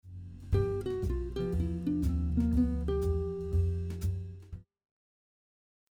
This jazz lick uses the dominant bebop scale which adds a half step between the b7 and root note.
jazz lick using the dominant bebop scale